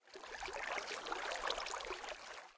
water.ogg